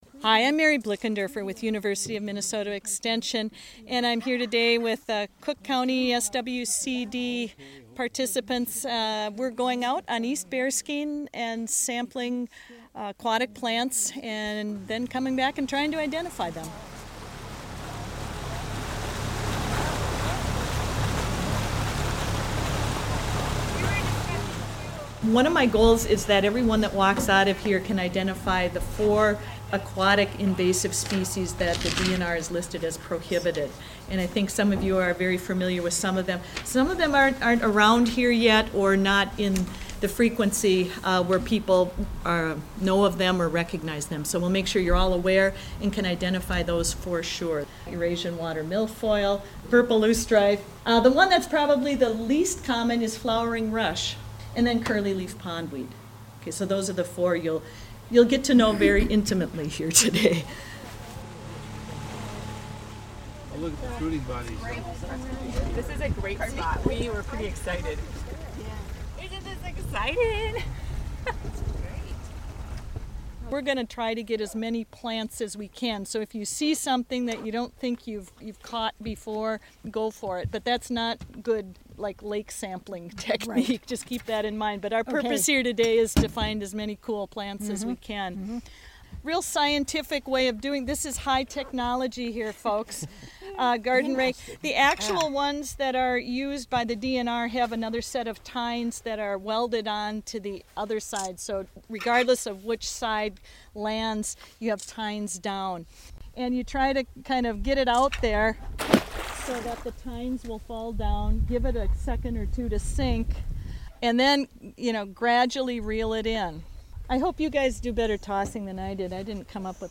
Recently Cook County Soil and Water hosted an outing on East Bearskin Lake. Participants got a closer look at invasive aquatic vegetation.